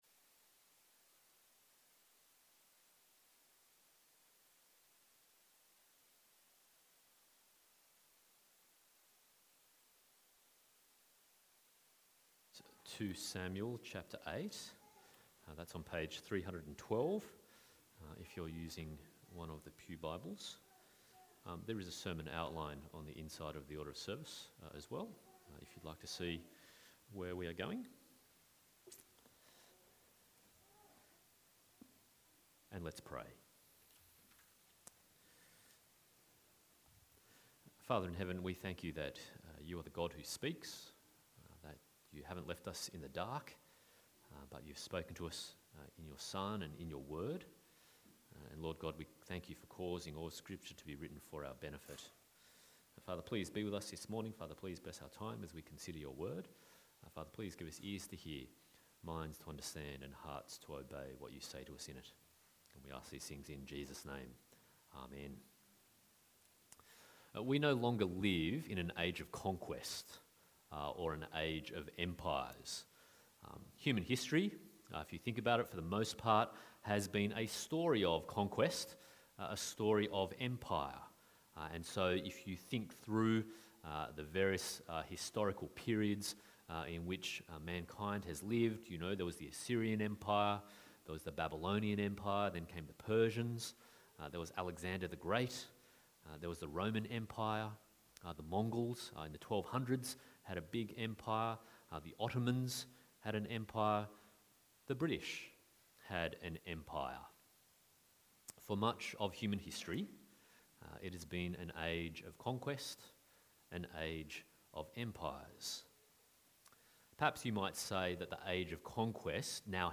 2 Samuel 1-10 Passage: 2 Samuel 8:1-18, Numbers 24:15-19, Revelation 7:9-12 Service Type: Sunday Morning